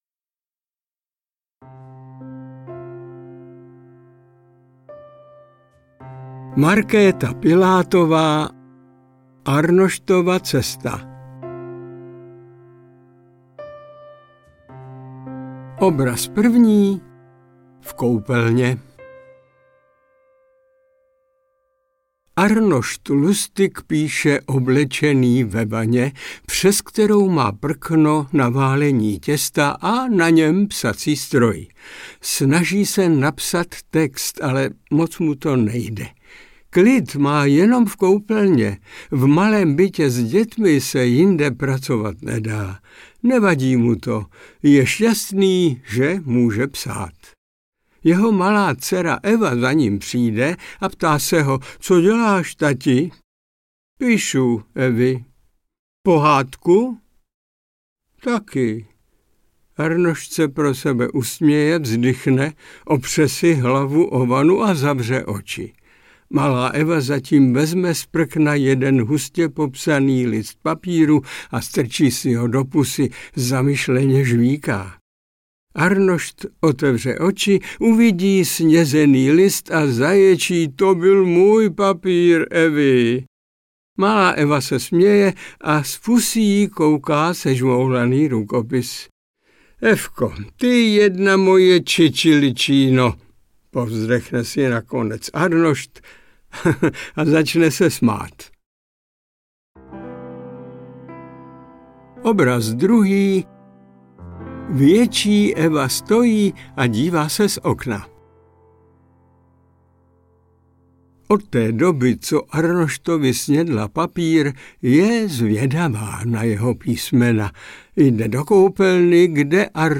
Interpret:  Miloň Čepelka